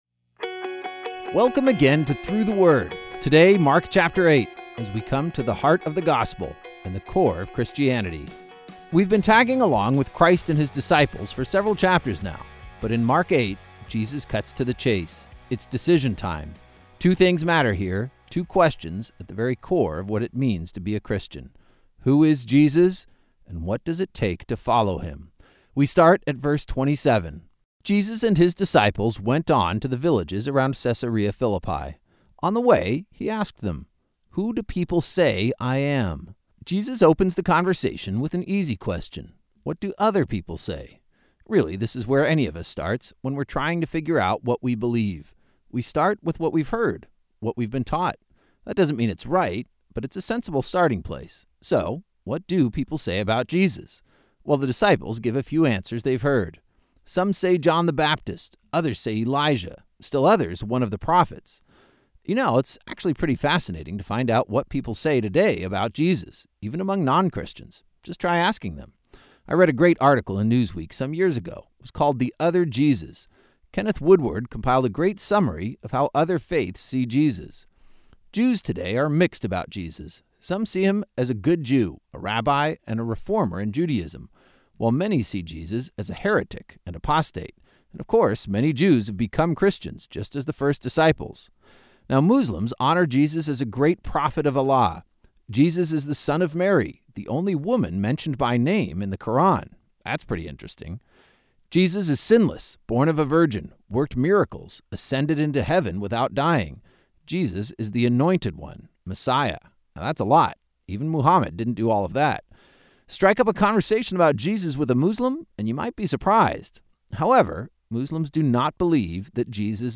Each journey is an epic adventure through several Bible books, as your favorite pastors explain each chapter in a friendly and compelling audio guide. Journey #1 is the perfect start with 24 days through Mark and Colossians.